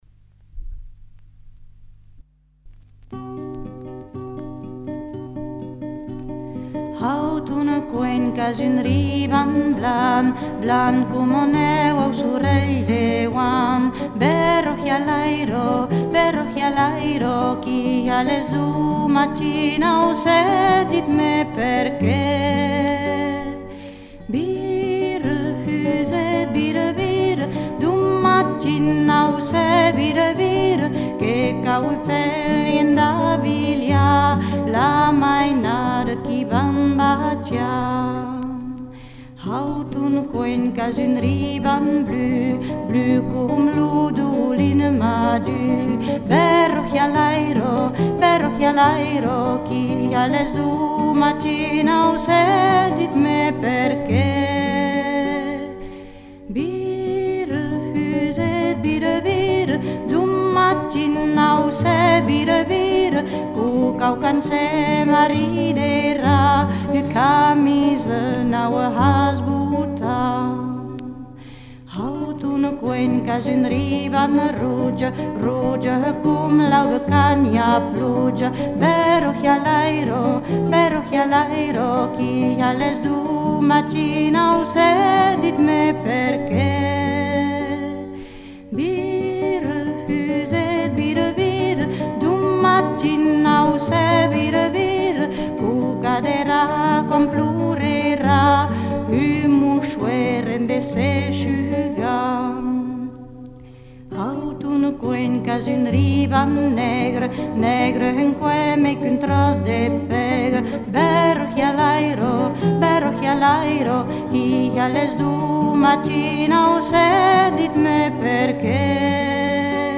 Genre : chanson-musique
Instrument de musique : guitare
Ecouter-voir : archives sonores en ligne